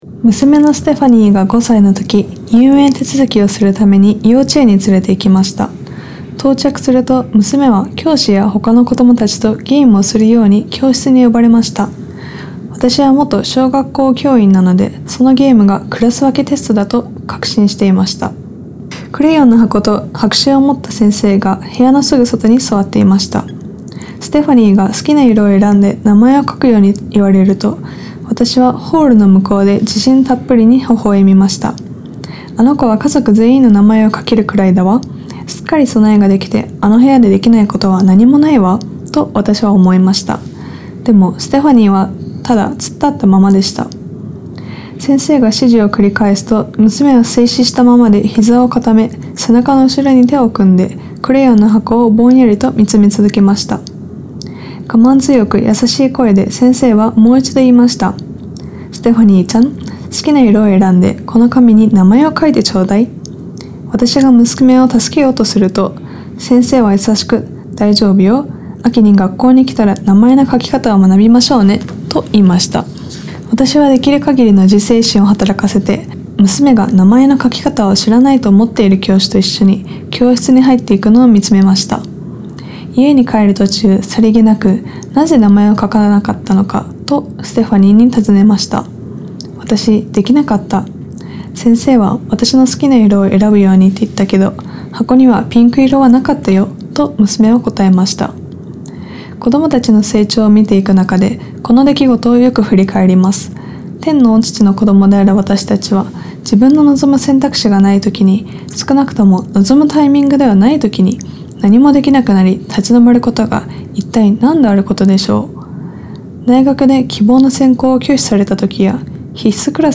ディボーショナル